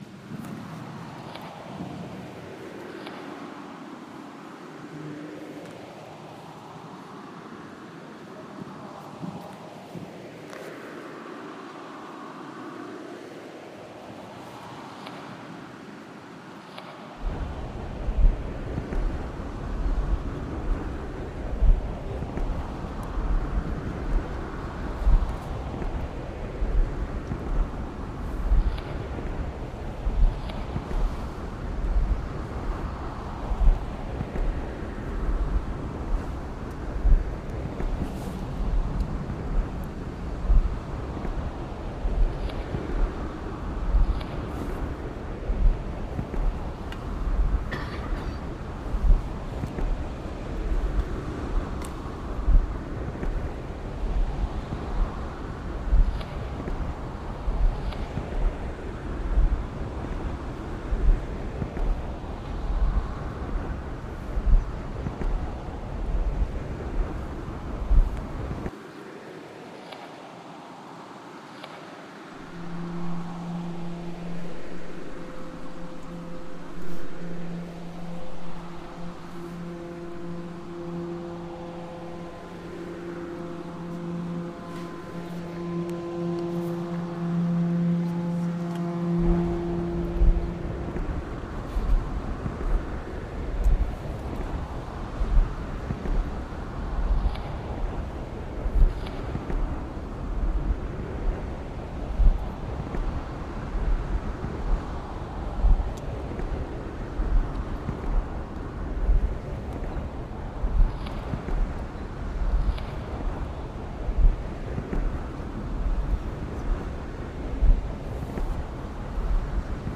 a reimagining of Mullion Cove